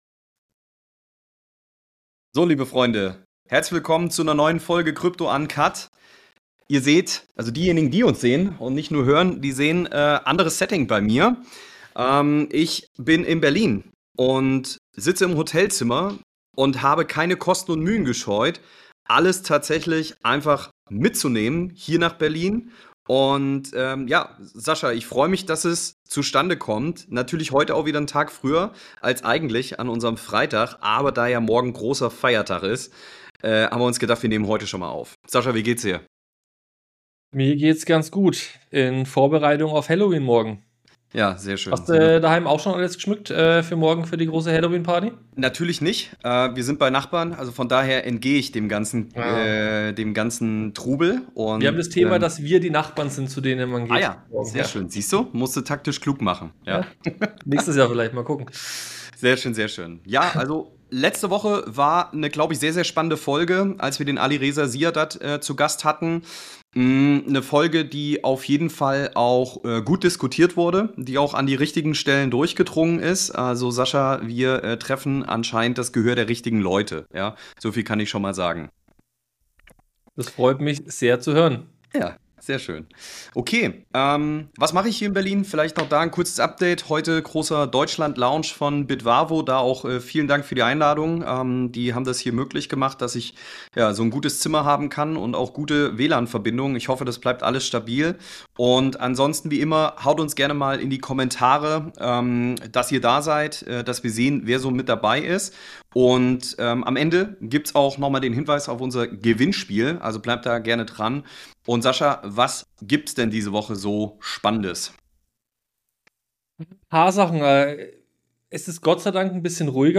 live aus Berlin beim Bitvavo-Launch